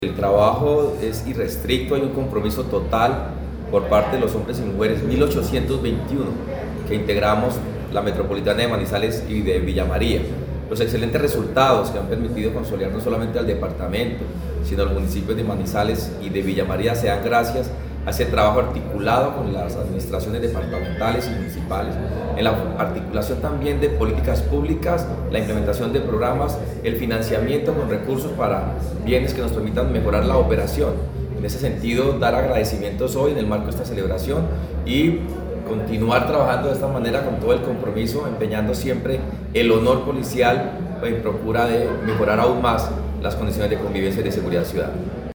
Coronel Dave Anderson Figueroa Castellanos, comandante de la Policía Metropolitana de Manizales